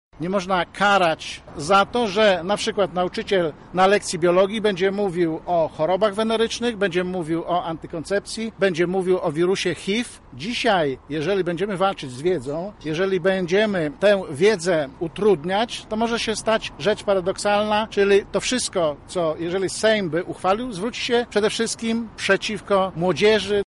W tej sprawie wypowiedział się Jacek Czerniak z SLD.